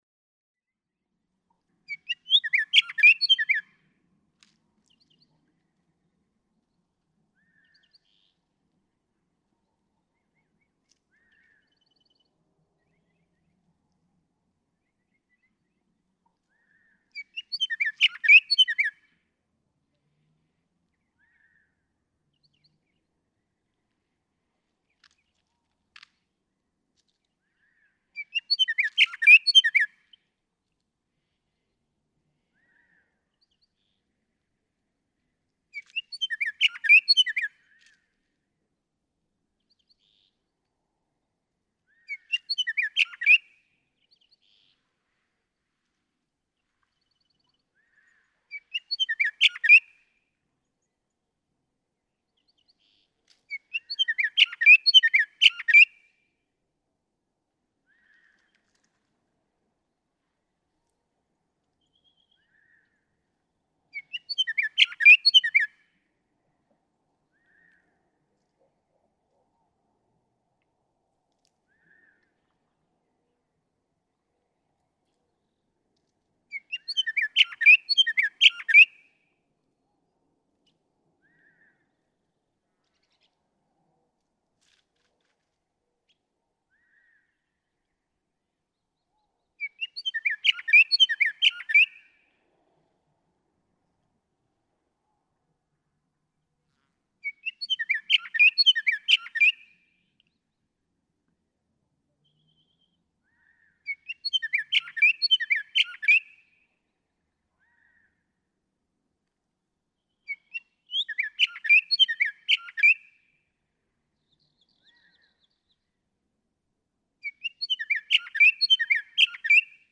Scott's oriole
One of the finest songsters from the arid Southwest.
Joshua Tree National Monument.
663_Scott's_Oriole.mp3